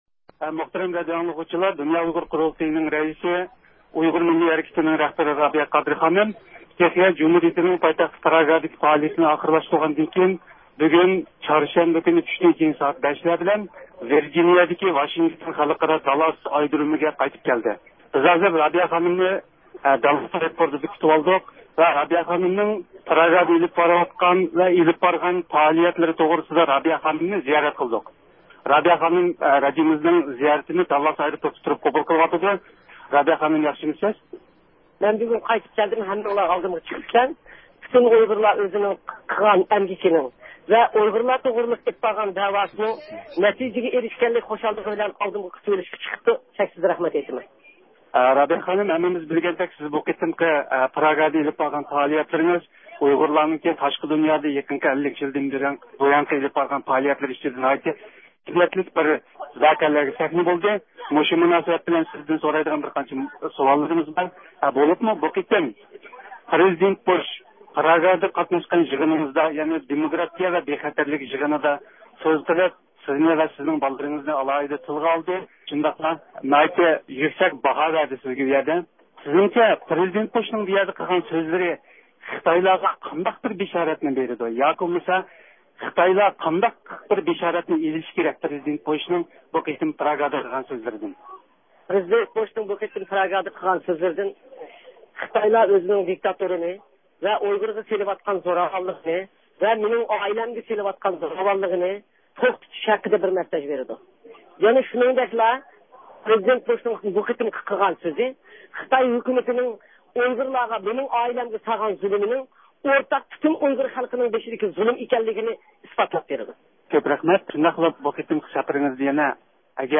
رابىيە قادىر چارشەنبە كۈنى چۈشتىن كېيىن ۋاشىنگتون داللاس ئايدۇرۇمىغا قونغاندىن كېيىن، بۇ قېتىمقى سەپىرى توغرىسىدا مۇخبىرىمىزنىڭ زىيارىتىنى قوبۇل قىلدى.